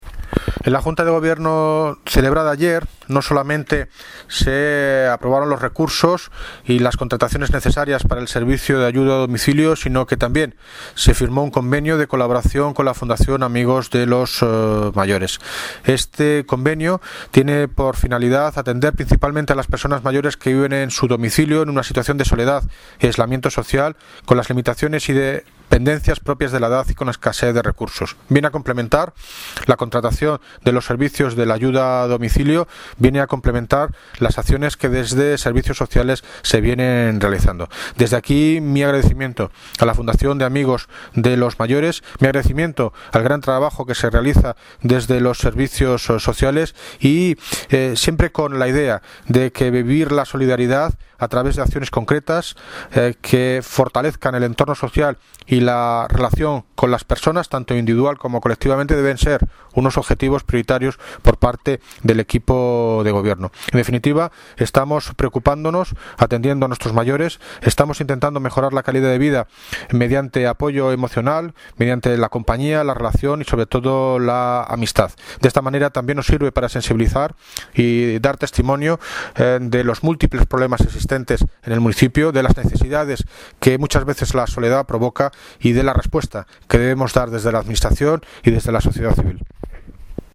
Audio - David Lucas (Alcalde de Móstoles) Sobre FUNDACION AMIGOS MAYORES